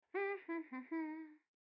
hum1.wav